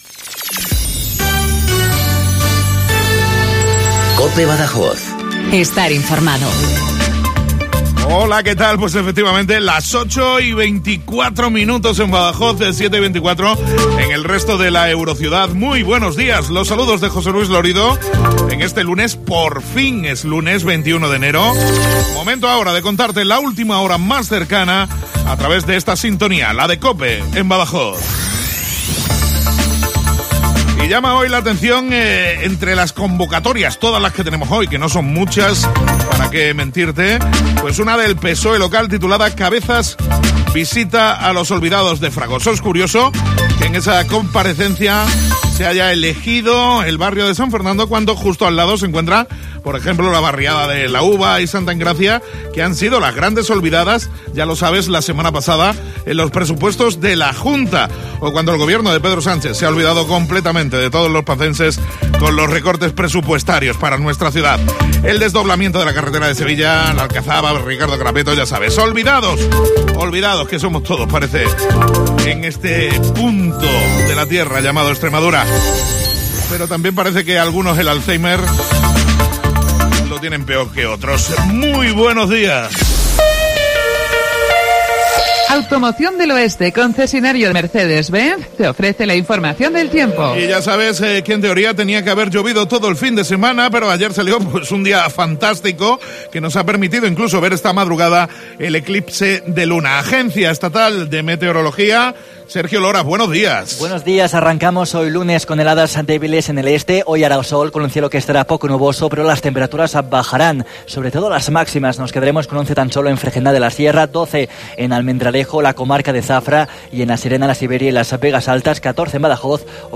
INFORMATIVO LOCAL BADAJOZ